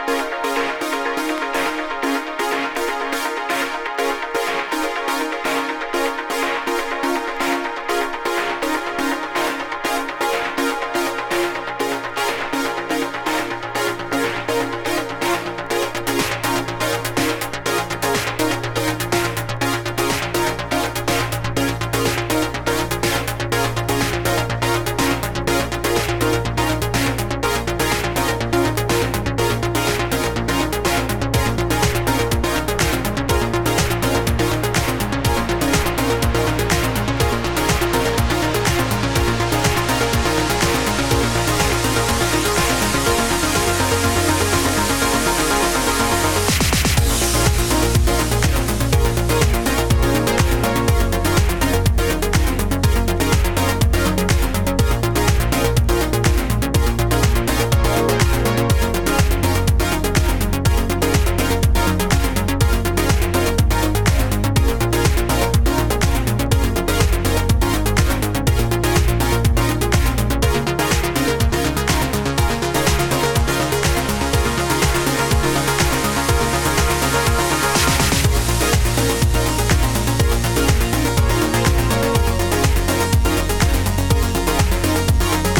キラキラしたProgressiveトラック